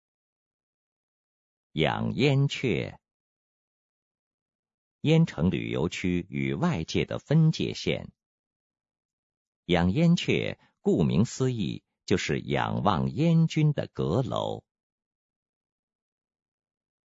语音导览